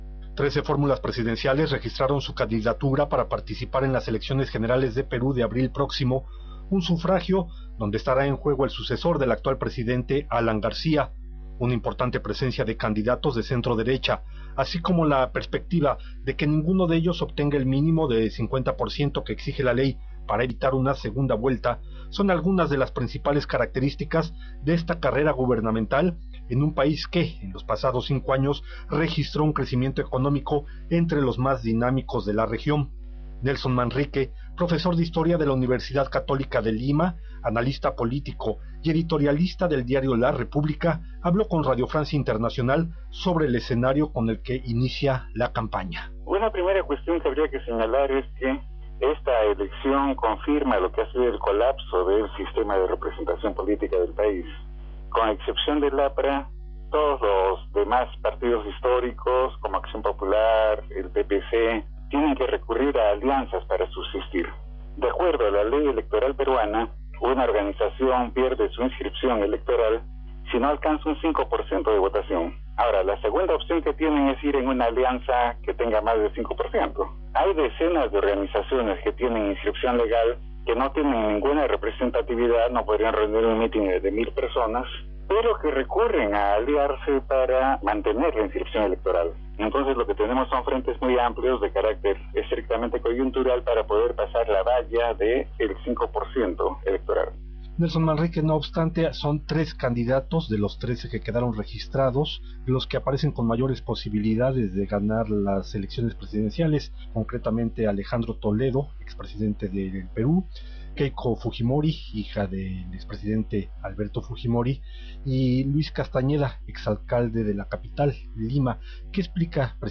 Alejandro Toledo, Luis Castañeda y Keiko Fujimori, son los favoritos de cara a las elecciones presidenciales de abril. Escuche el informe de Radio Francia Internacional.